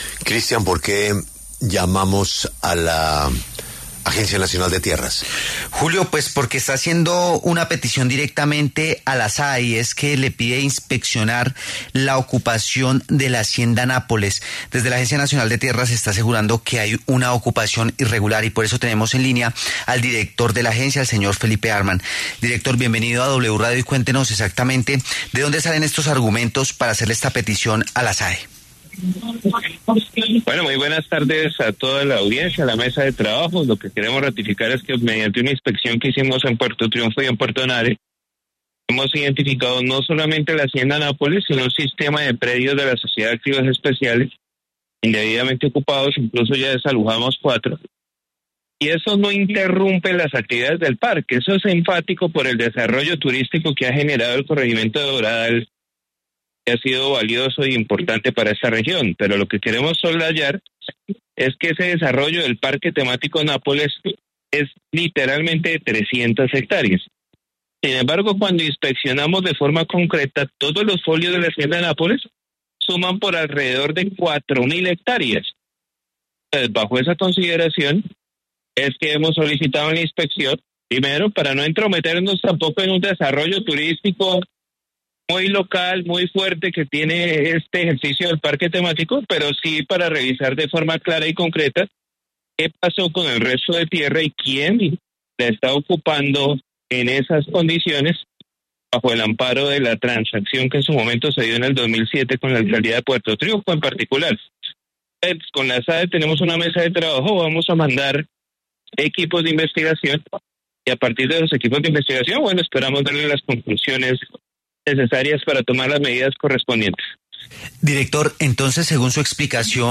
En entrevista con W Radio, el director de la Agencia Nacional de Tierras (ANT), Felipe Harman, confirmó que pidió a la Sociedad de Activos Especiales (SAE) que realice una inspección sobre la Hacienda Nápoles para validar quiénes y con qué figura están haciendo la ocupación de más de 3.000 hectáreas de ese predio.